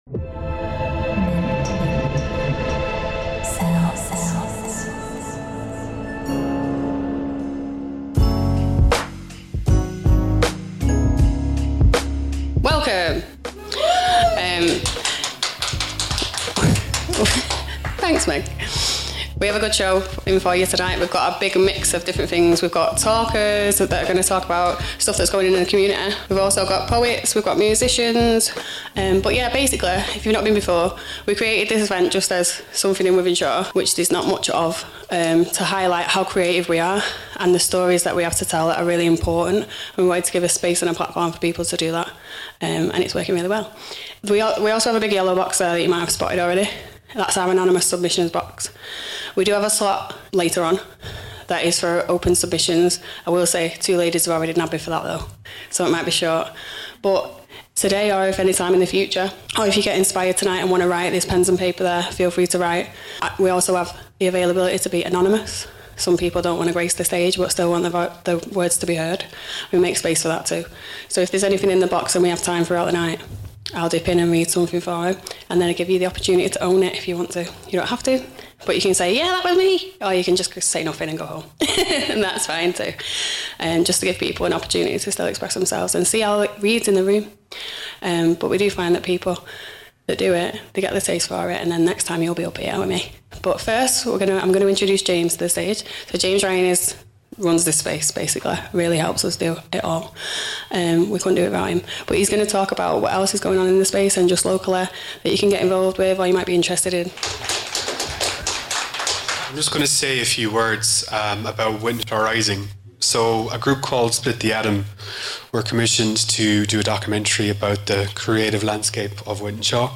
In January Liveinshawe hosted a poetry and spoken word and music event at the Wythenshawe Creative space. Mint sounds went along to record some of the performances of local people and their poetry.